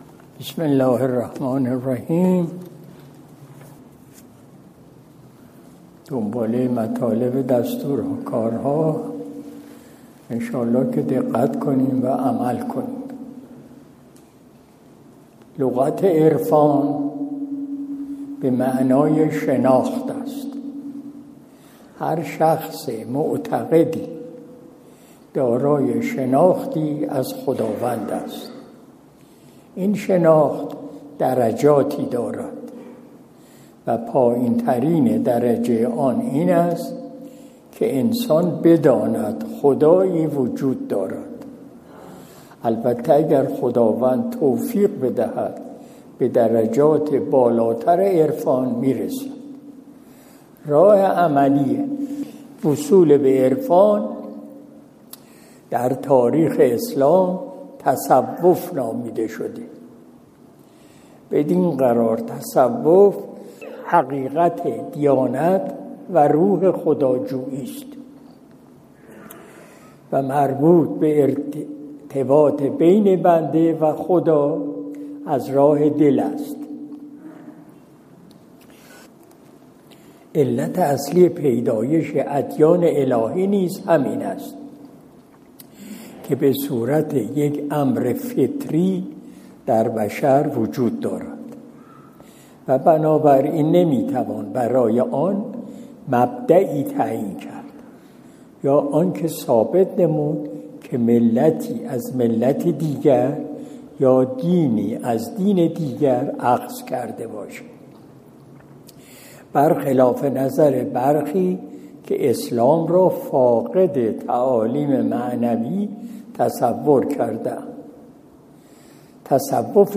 مجلس شب دوشنبه ۲۸ اسفند ماه ۱۴۰۱ شمسی